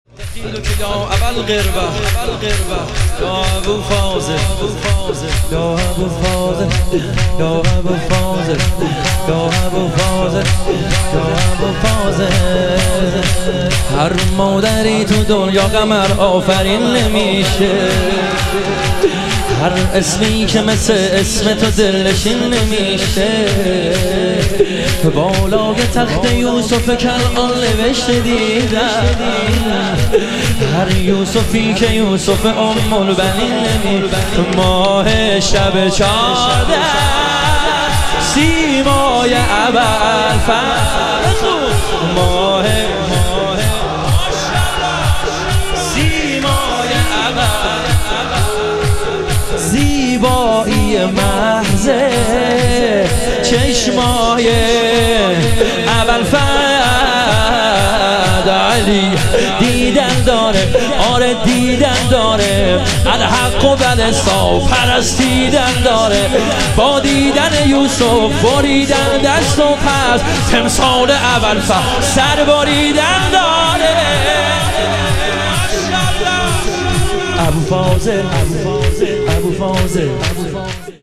شهادت امام کاظم علیه السلام - شور